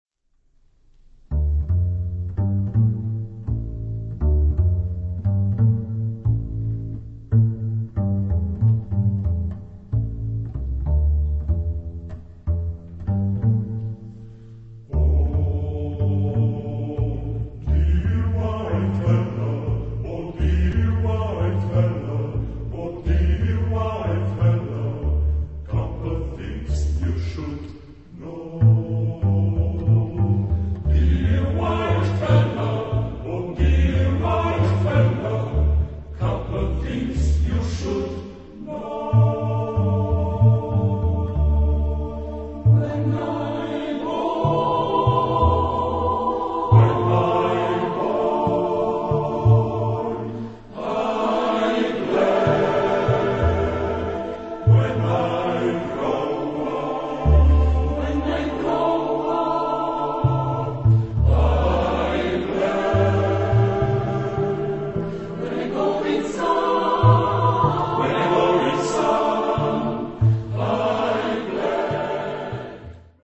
Genre-Style-Forme : Profane ; Chœur
Caractère de la pièce : ironique ; humoristique
Type de choeur : SSAATTBB  (8 voix mixtes )
Tonalité : sol mode de ré ; ré mode de ré ; la mode de la